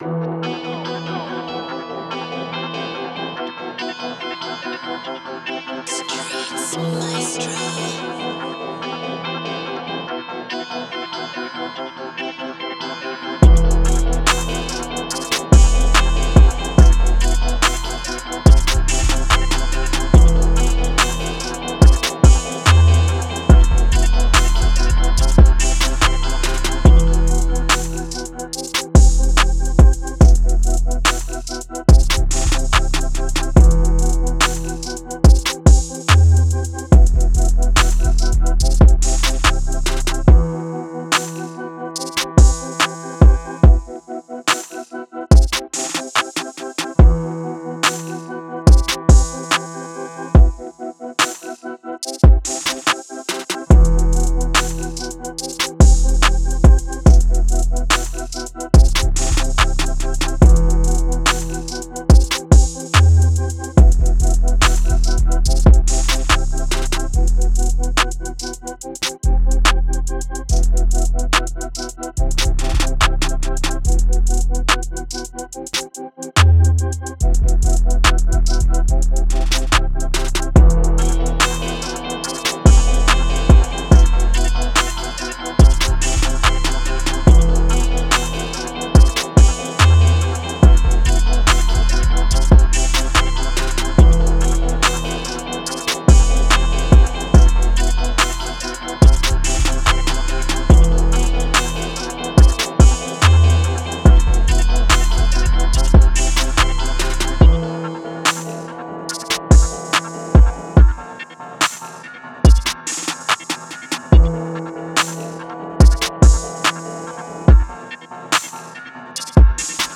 Moods: dramatic, hard, dark
Genre: Rap
Tempo: 143
BPM 130
Dramatic Type Beat